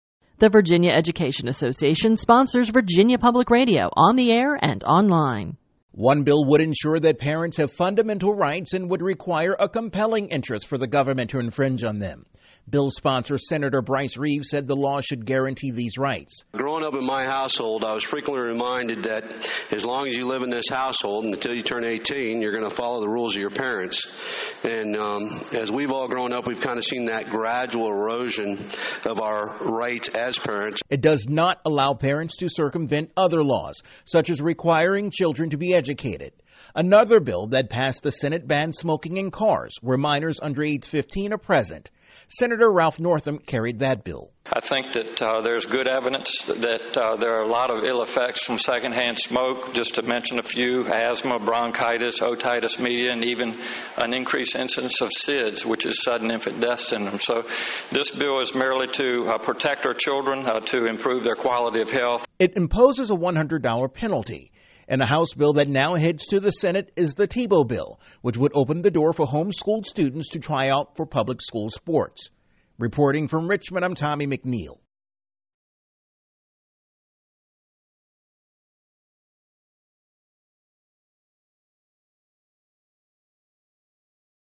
This entry was posted on February 1, 2013, 12:32 pm and is filed under Daily Capitol News Updates.